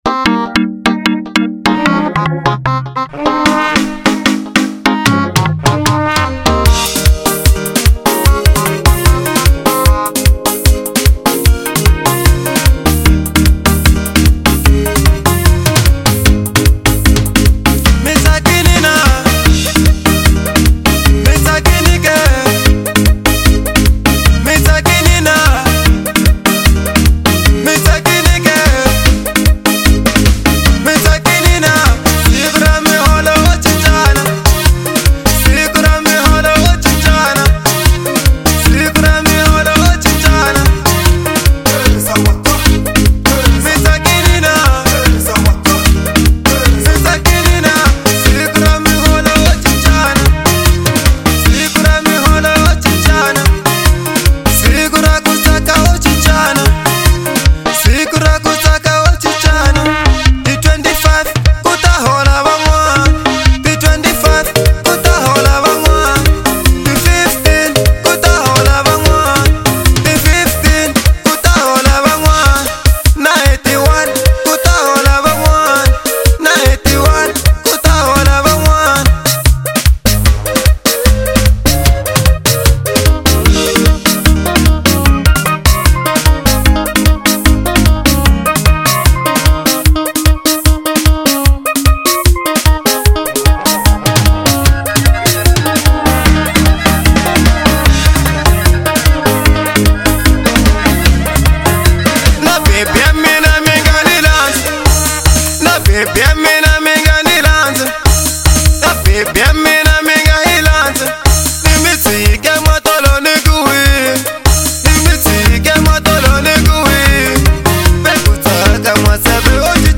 04:39 Genre : Xitsonga Size